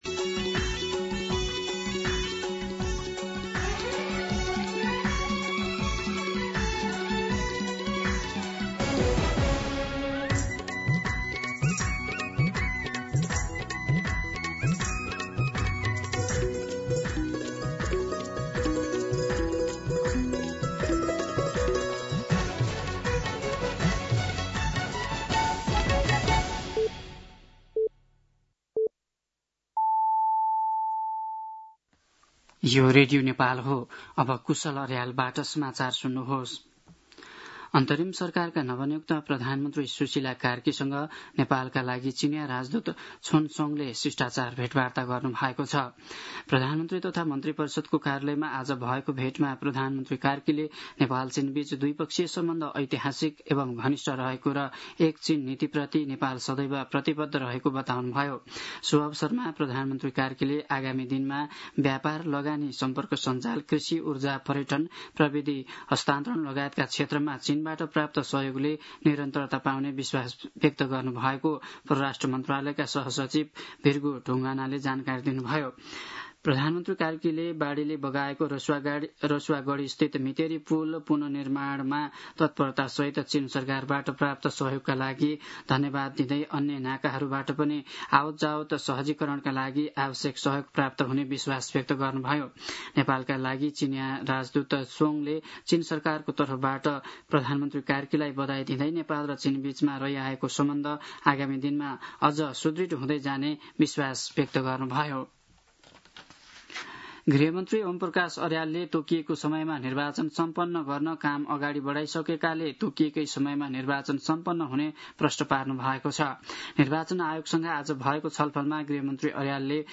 दिउँसो ४ बजेको नेपाली समाचार : २ असोज , २०८२